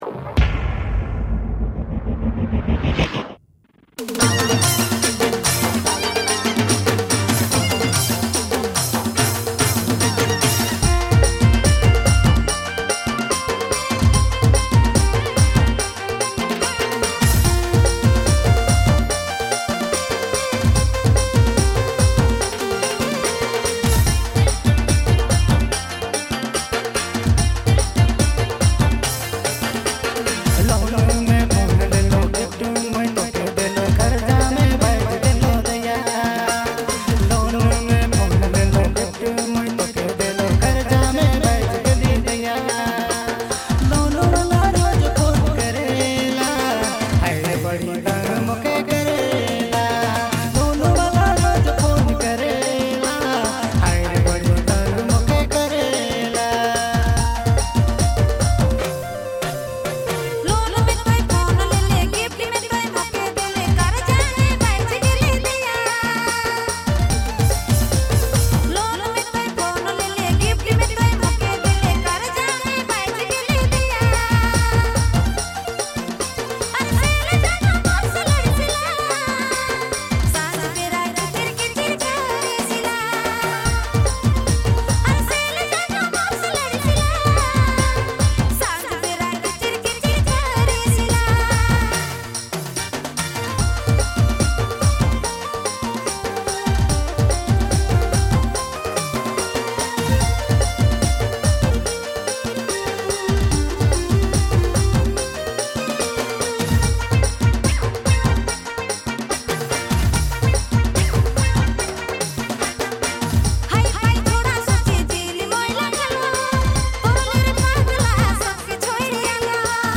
Dj Remixer
February Months Latest Nagpuri Songs